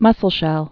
(mŭsəl-shĕl)